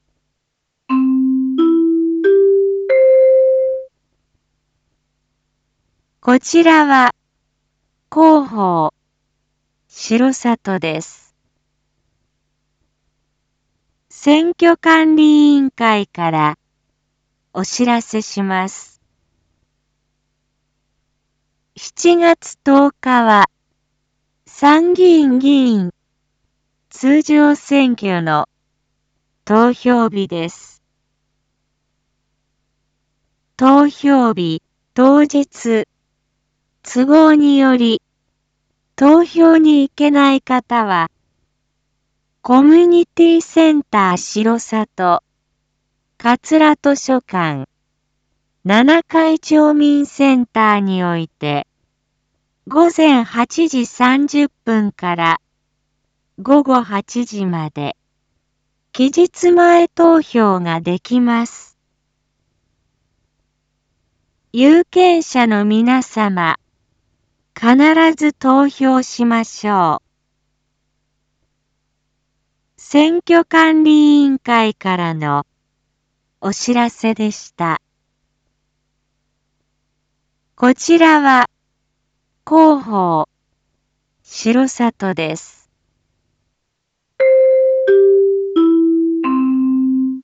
Back Home 一般放送情報 音声放送 再生 一般放送情報 登録日時：2022-07-02 19:01:32 タイトル：参議院議員通常選挙（全地区期日前投票について） インフォメーション：こちらは広報しろさとです。